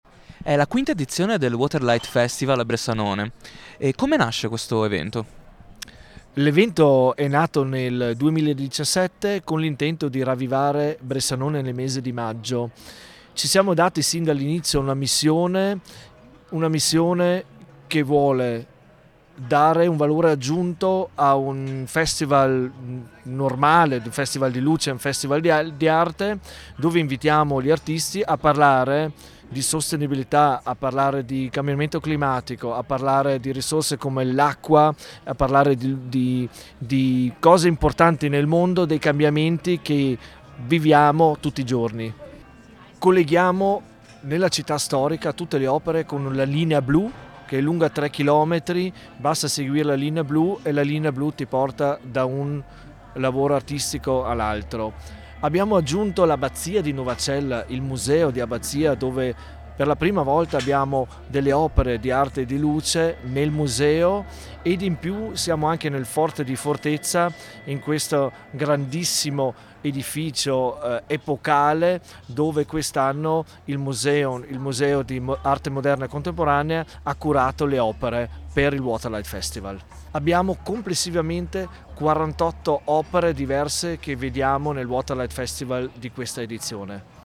Ecco le dichiarazioni raccolte durante la serata di inaugurazione: